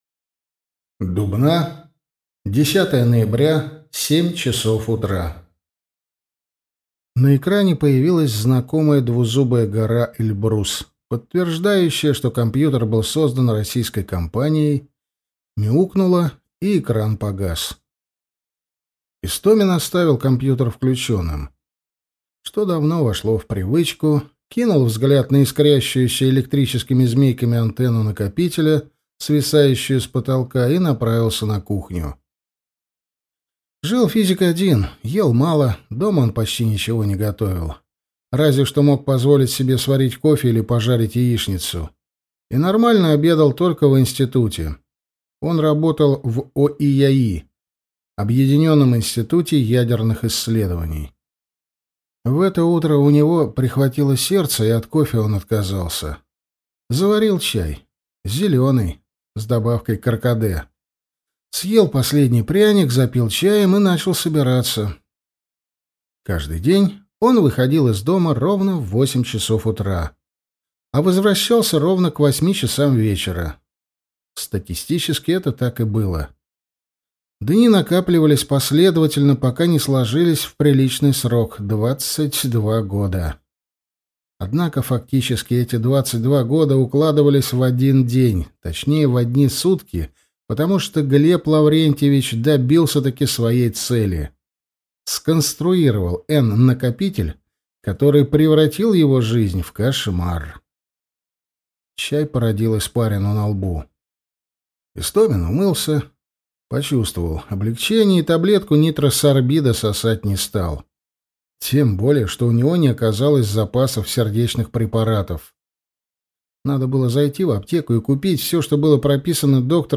Аудиокнига Время убивает | Библиотека аудиокниг